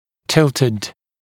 [‘tɪltɪd][‘тилтид]наклоненный; накрененный